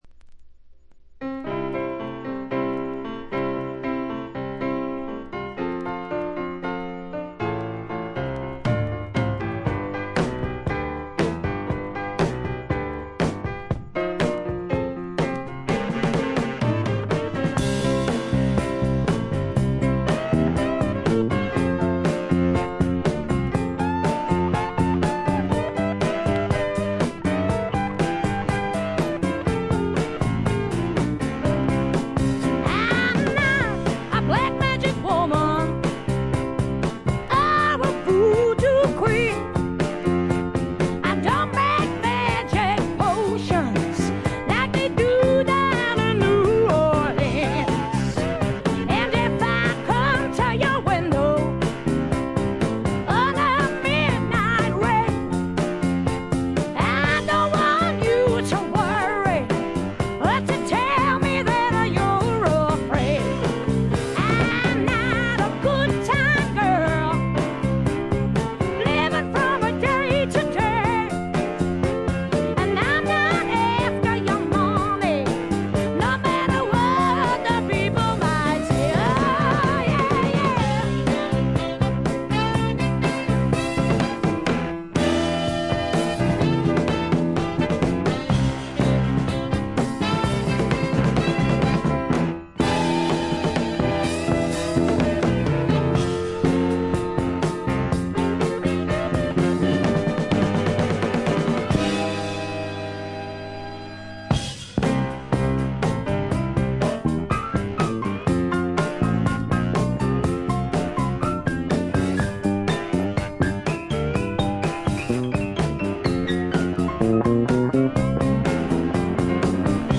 重厚なスワンプロックの大傑作です。
試聴曲は現品からの取り込み音源です。